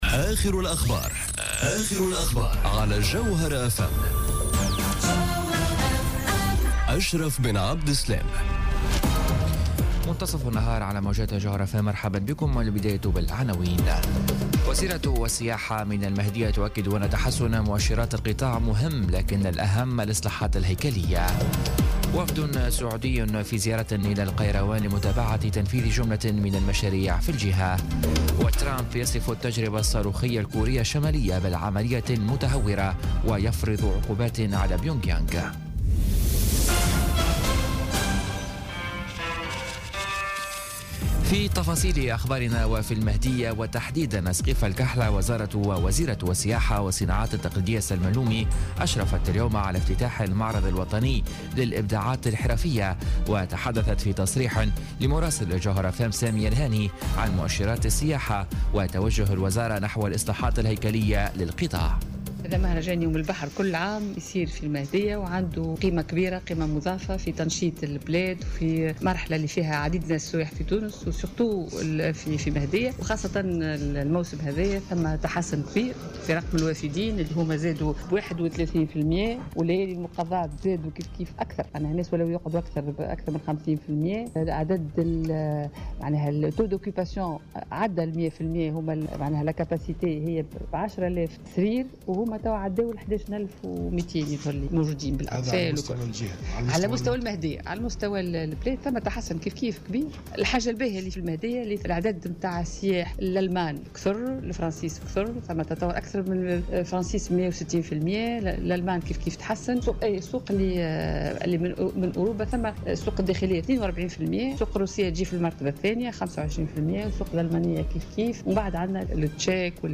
نشرة أخبار منتصف النهار ليوم السبت 29 جويلية 2017